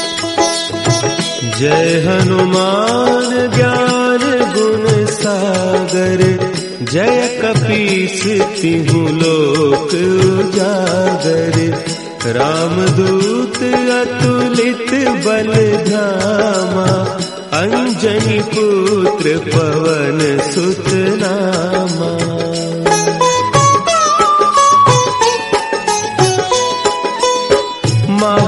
• Category: Devotional / Hanuman Bhajan